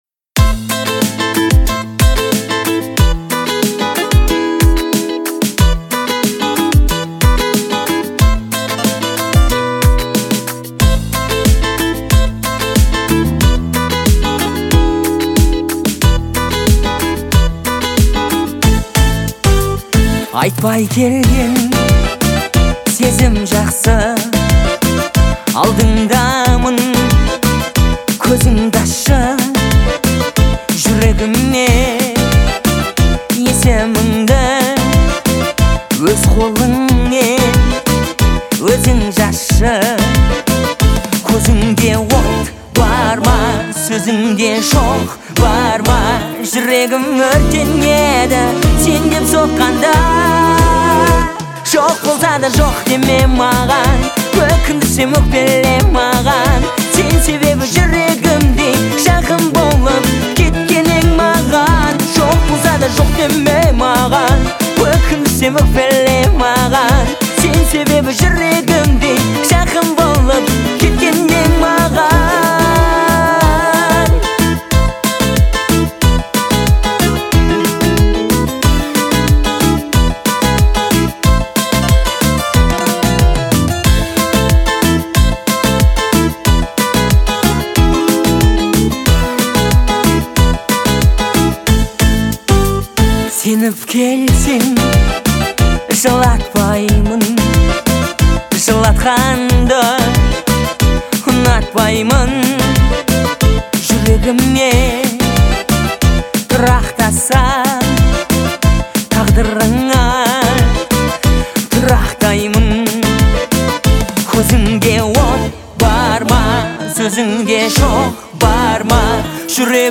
выразительный вокал и душевные мелодии